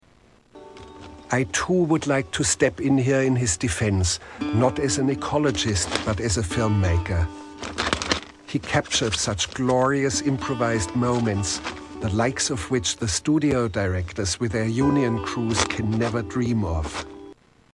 Grizzly Man Narration 2